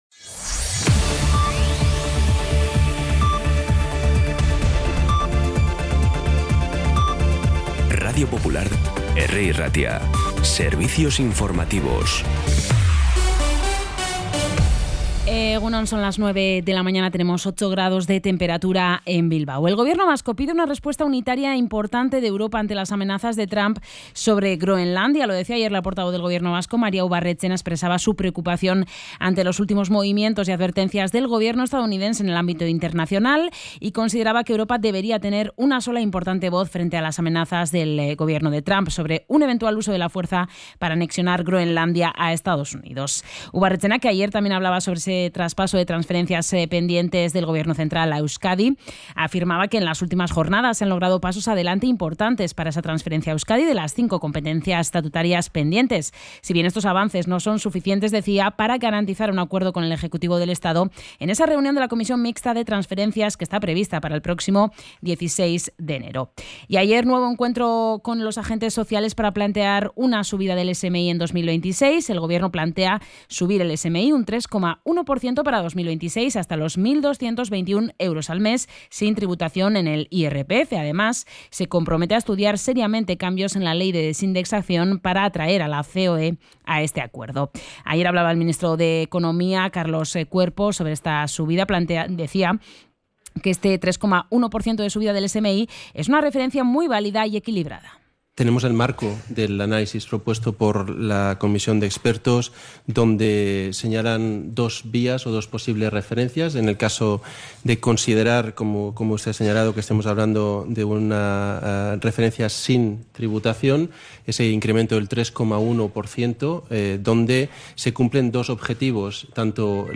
La redacción de informativos de Radio Popular – Herri Irratia trabaja durante todo el día para ofrecerte el resumen informativo más compacto.
Los titulares actualizados con las voces del día. Bilbao, Bizkaia, comarcas, política, sociedad, cultura, sucesos, información de servicio público.